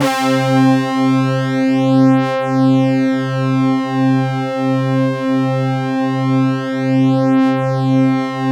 BRASS2 MAT12.wav